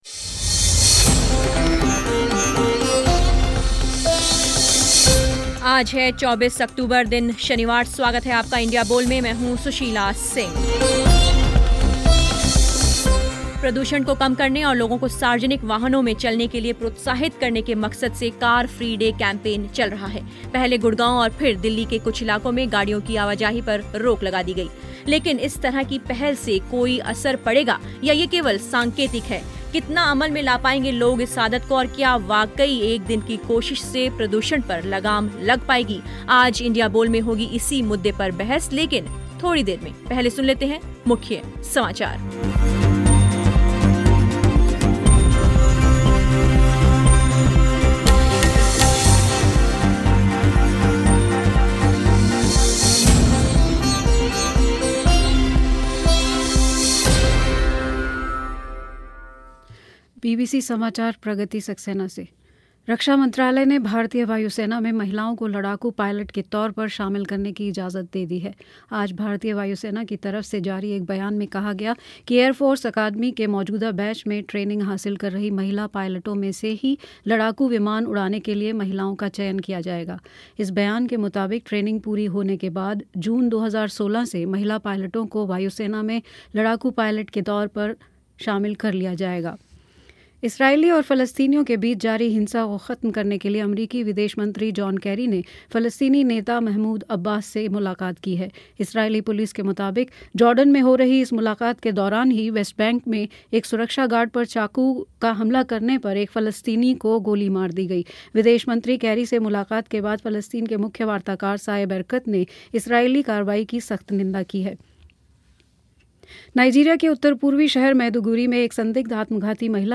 कार फ्री डे मनाने से क्या प्रदूषण को रोकने में मदद मिलेगी या ये केवल सांकेतिक है? क्या लोग सार्वजनिक वाहनों का इस्तेमाल करने के लिए प्रेरित होंगे. इसी मुद्दे पर हुई इंडिया बोल में बहस